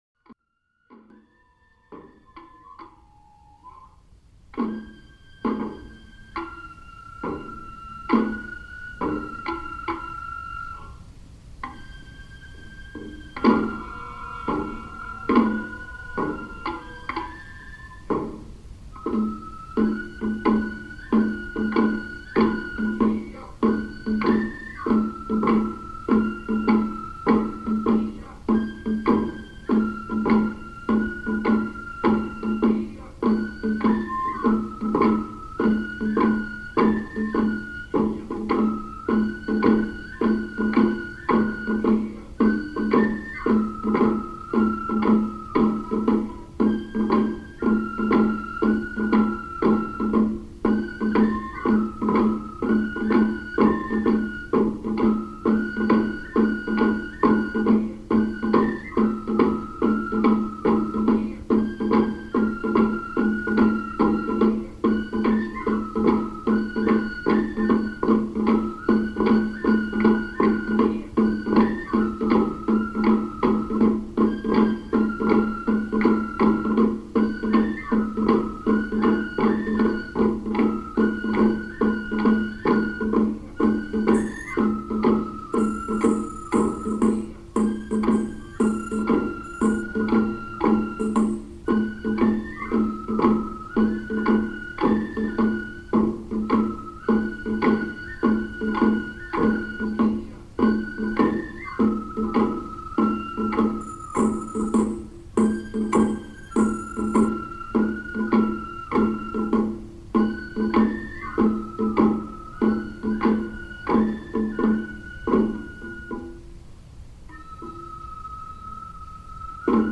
大太鼓，小太鼓，鼓，能管で演奏されます。
拍子は速いです。
車切り(昭和43年)を聴く（MP3形式３MB）（～0:18まで三番叟、0:19～1:55まで車切、それ以後再び三番叟）